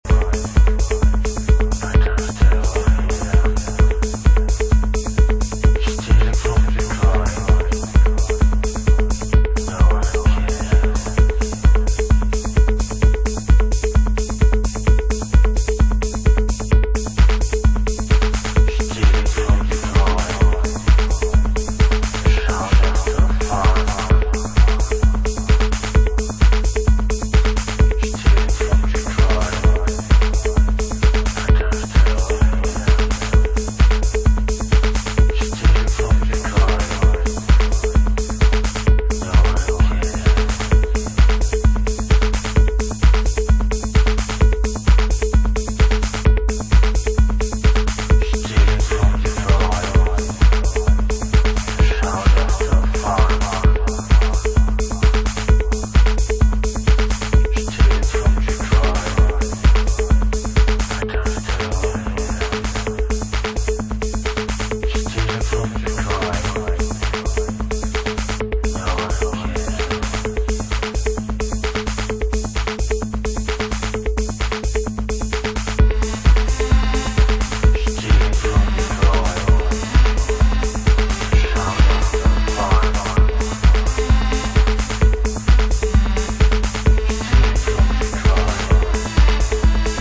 classic Detroit influenced technohouse
Techno